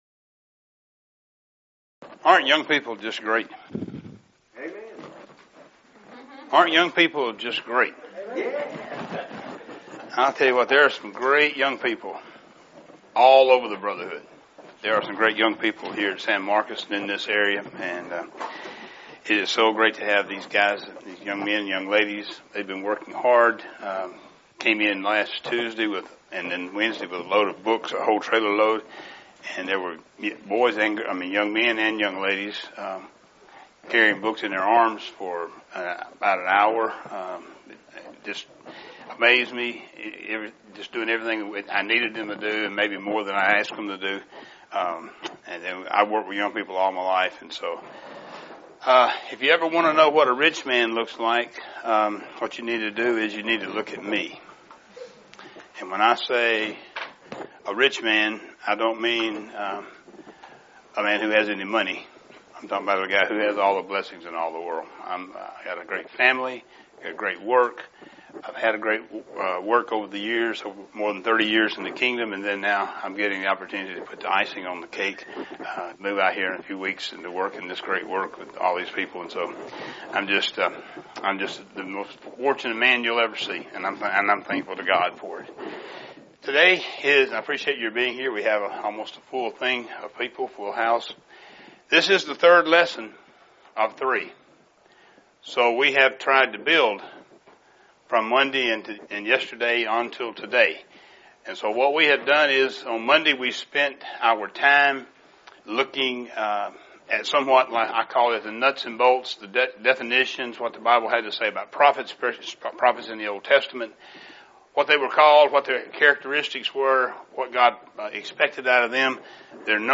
Event: 2019 Focal Point
Preacher's Workshop